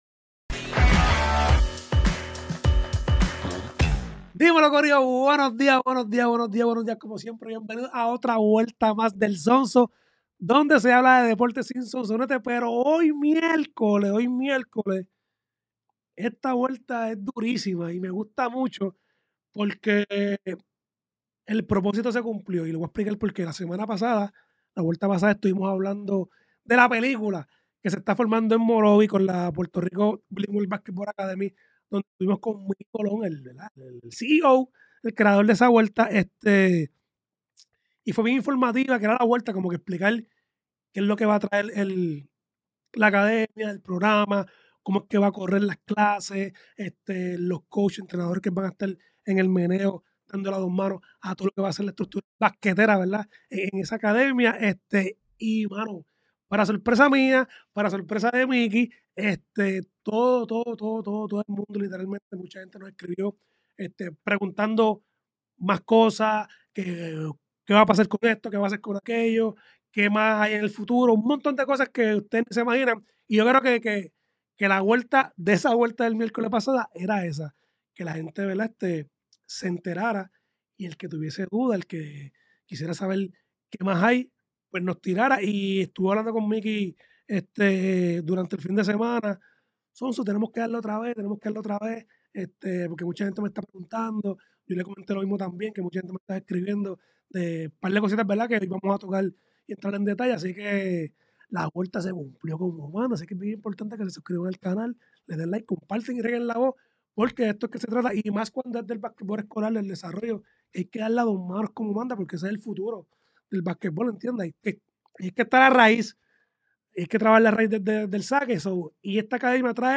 Grabado en GW-5 Studio